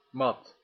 Ääntäminen
IPA: /mɑt/